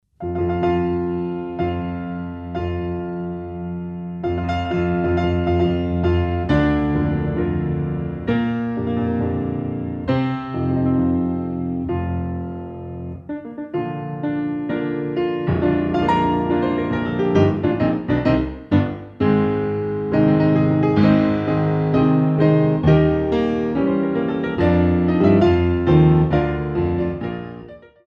Marche